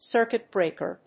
アクセントcírcuit brèaker